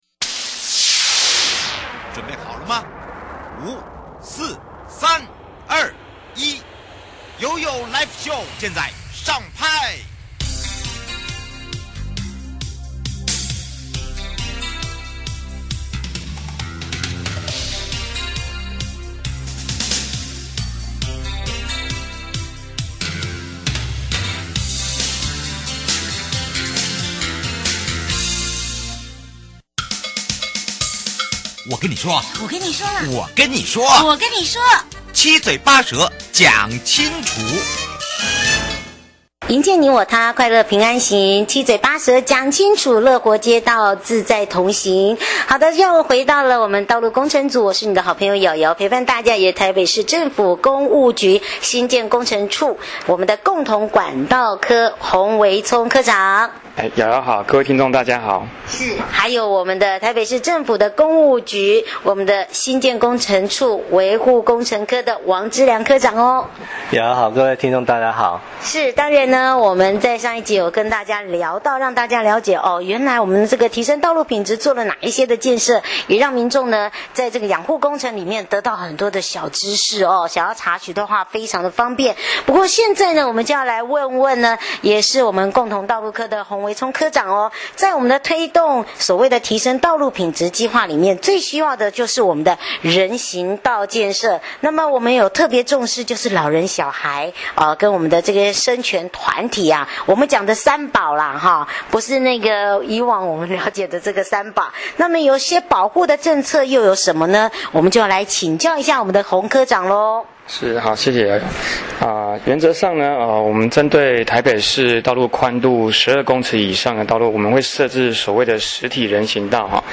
受訪者： 營建你我他 快樂平安行 七嘴八舌講清楚樂活街道自在同行-基隆市政府針對提升道路品質計畫做了哪些建設?